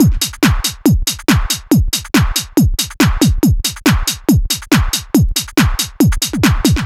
NRG 4 On The Floor 019.wav